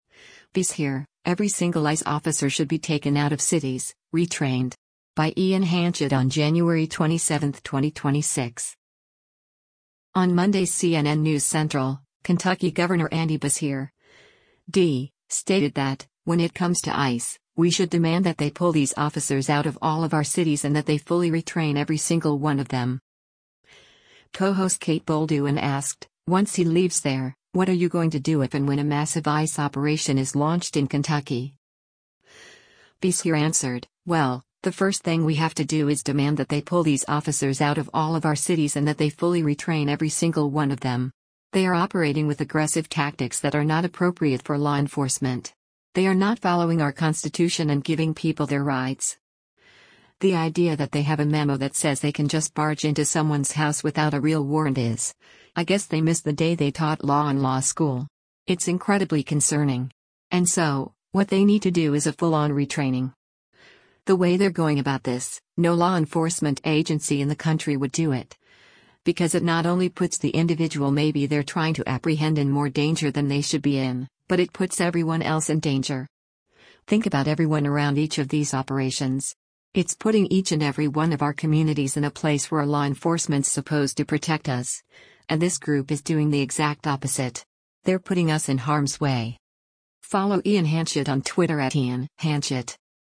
On Monday’s “CNN News Central,” Kentucky Gov. Andy Beshear (D) stated that, when it comes to ICE, we should “demand that they pull these officers out of all of our cities and that they fully retrain every single one of them.”
Co-host Kate Bolduan asked, “Once he leaves there, what are you going to do if and when a massive ICE operation is launched in Kentucky?”